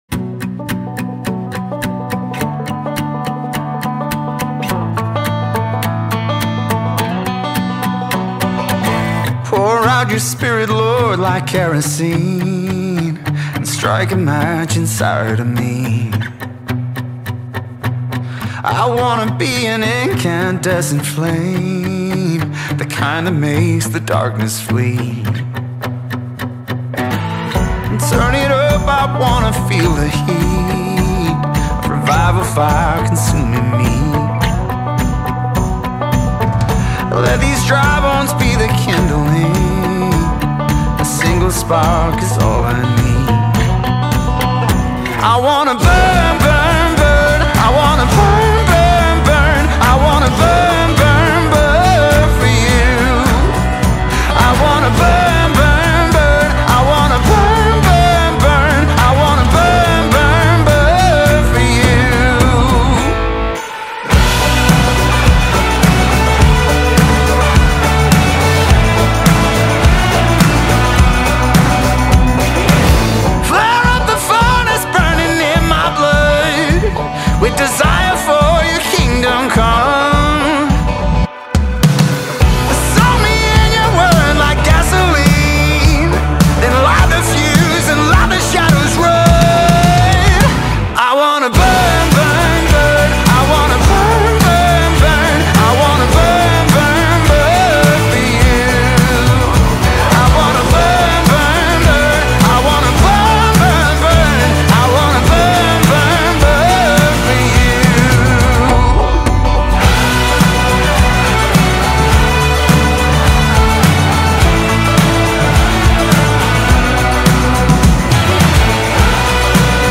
108 просмотров 44 прослушивания 3 скачивания BPM: 105